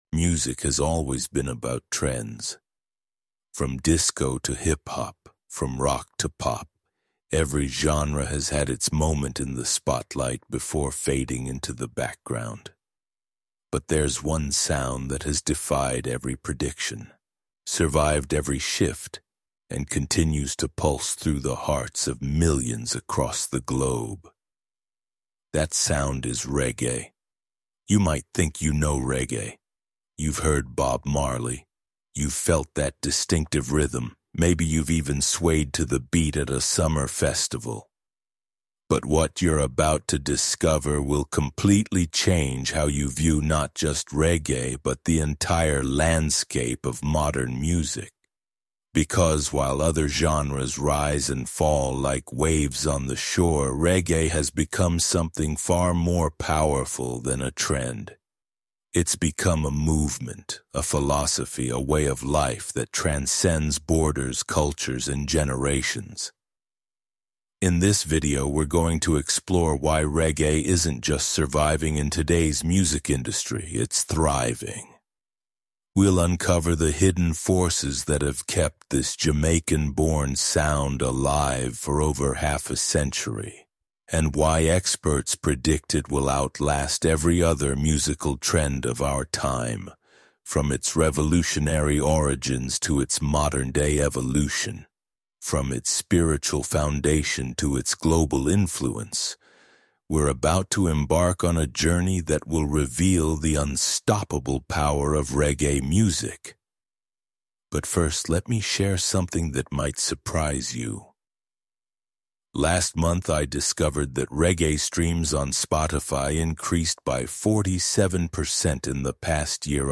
Guided by history experts and veteran musicians, we trace reggae’s roots through colonization, maroon communities, black history, and the enduring spirit of island life. From Kingston sound-systems to the Costa Rican Caribbean coast, discover untold truths about cultural resilience, the rise of dancehall, and how artists—from Bob Marley to Vybz Kartel—turned Jamaica’s struggles into a global soundtrack of freedom.